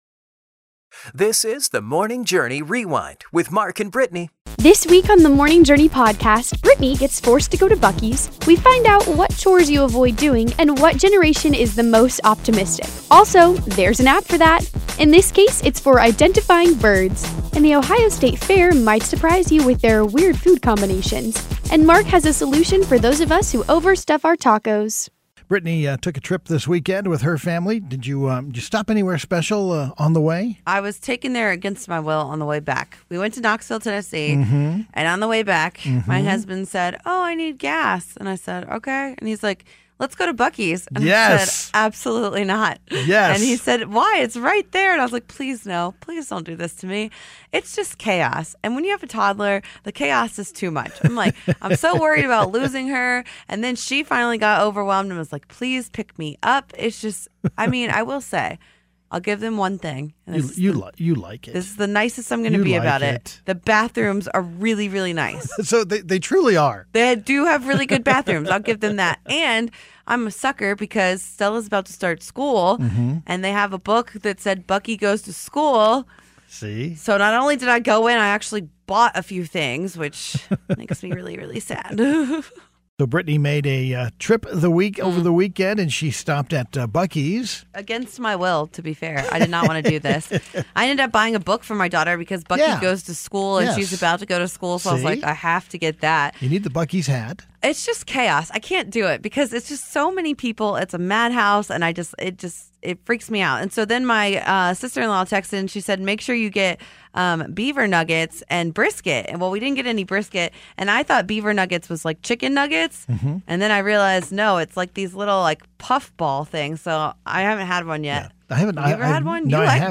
This week's episode includes a Shark Week interview with Hillcity Aqua Zoo!